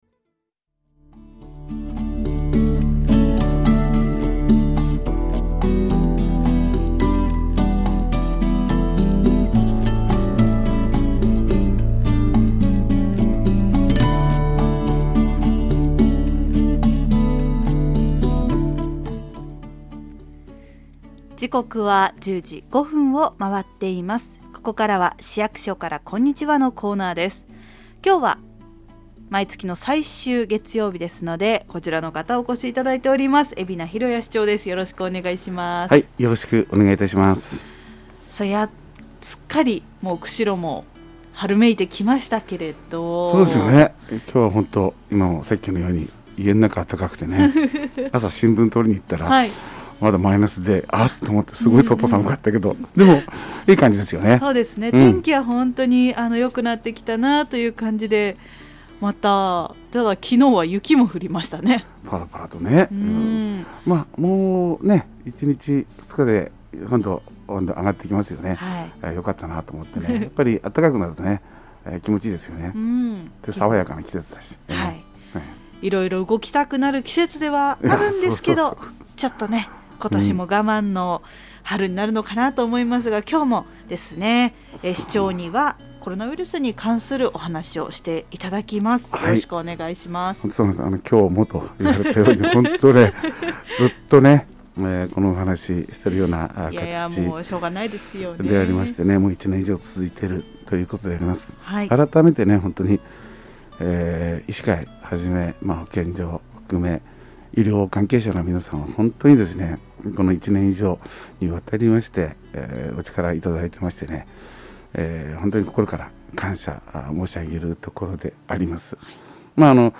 2021年度 ラジオ広報「市役所からこんにちは」